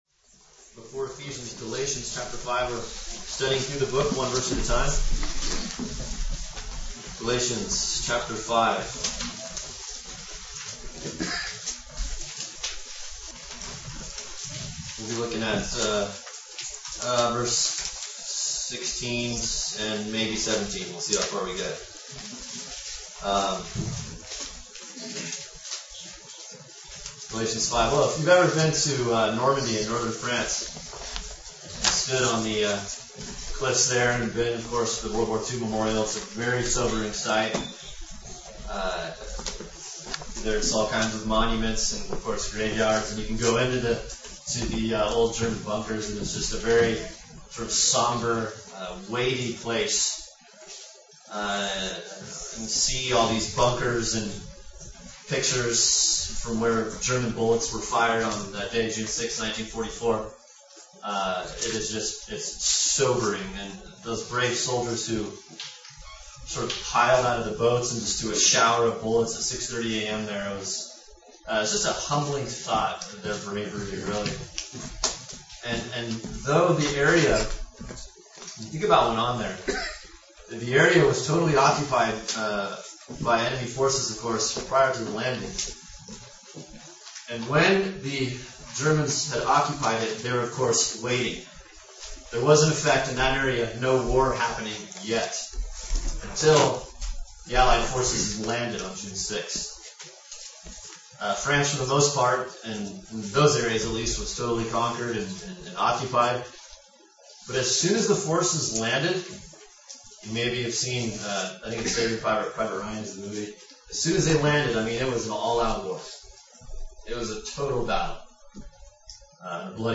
(The digital noise in the recording goes away after about 7 minutes.)